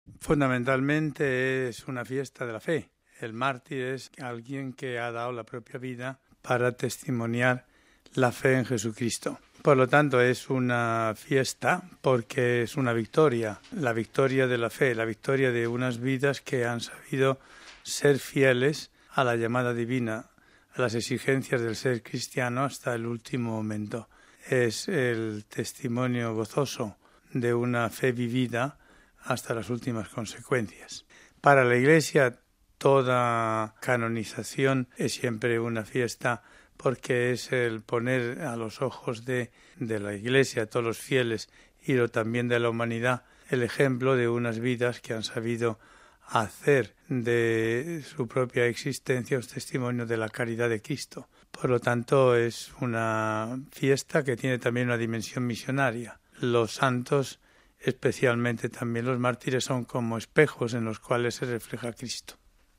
Tenemos con nosotros hoy al presidente emérito de Pontificio Consejo para los textos legislativos, cardenal Julián Herranz.